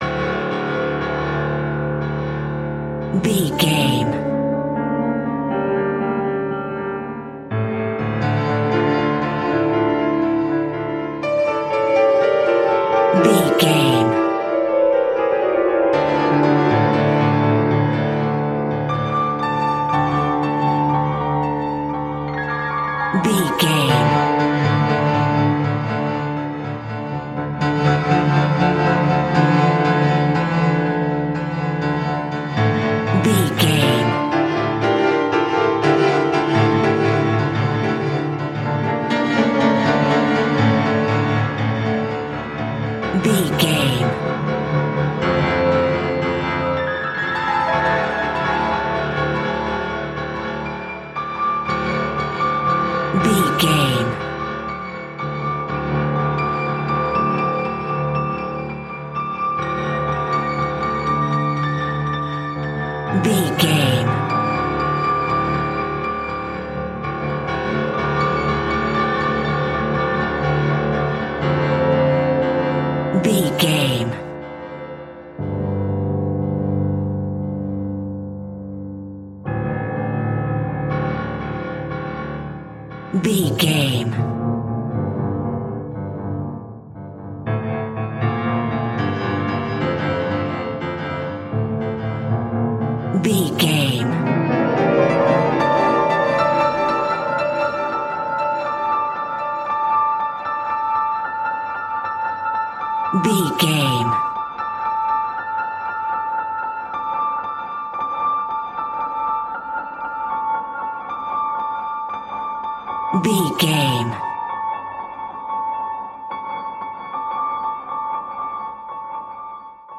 Atonal
tension
ominous
eerie